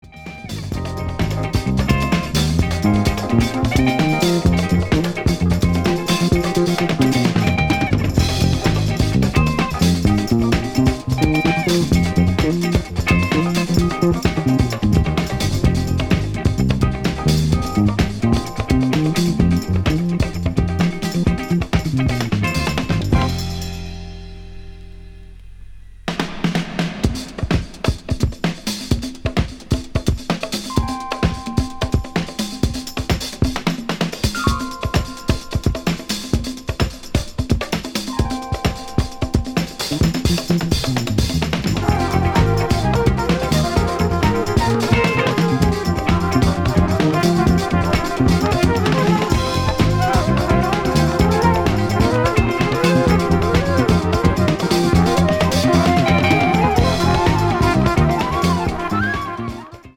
jazz-funk
trumpet